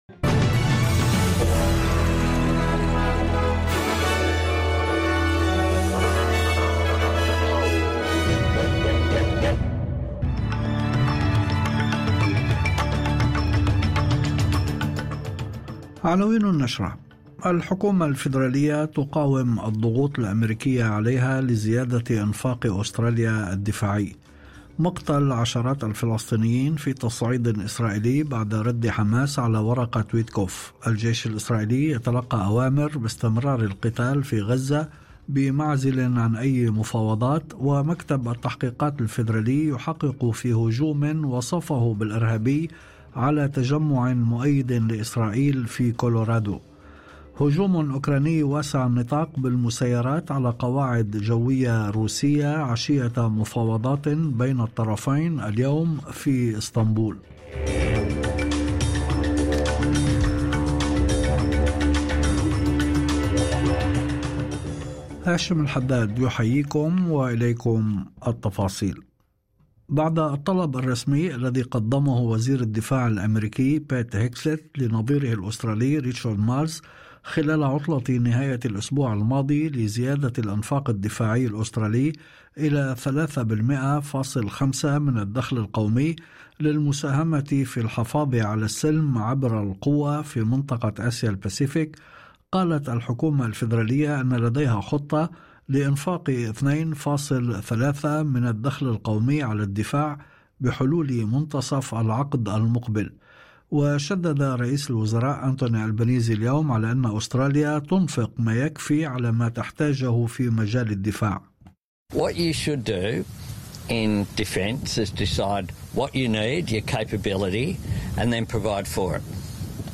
نشرة أخبار المساء 02/06/2025